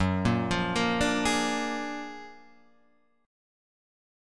Gb+M9 Chord